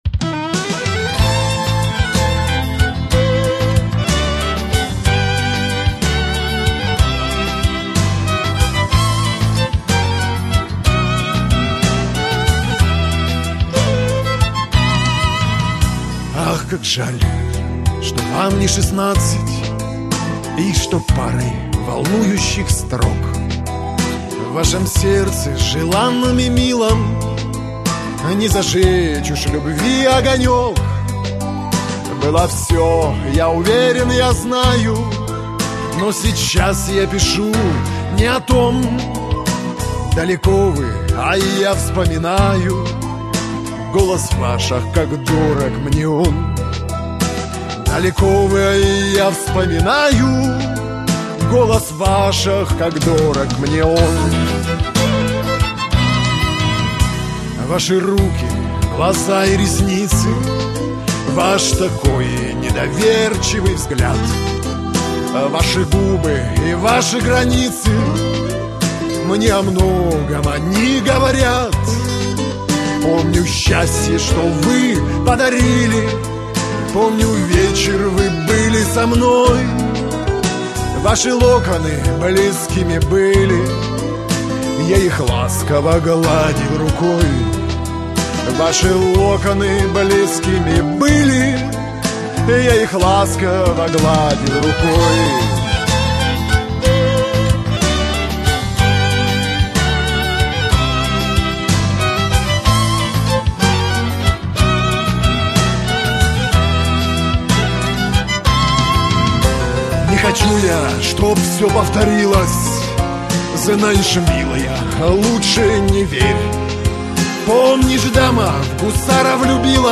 исполняемых в стиле «русский шансон».